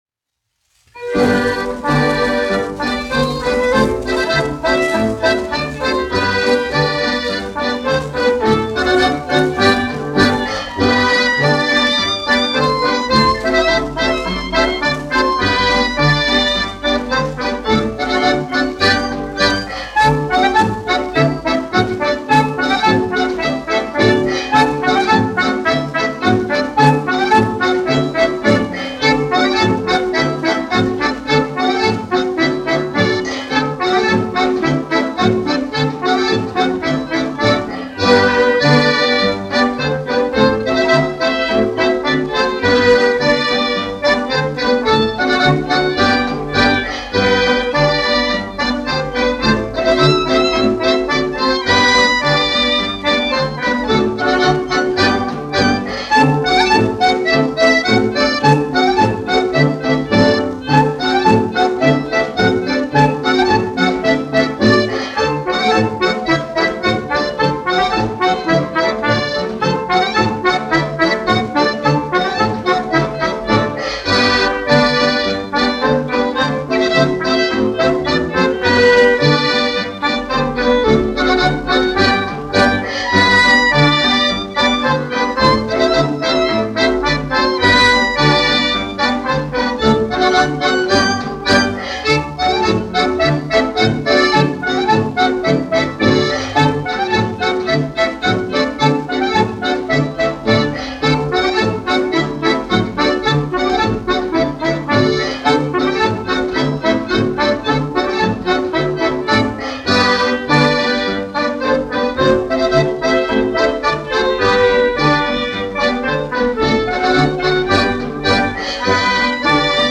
1 skpl. : analogs, 78 apgr/min, mono ; 25 cm
Latviešu tautas dejas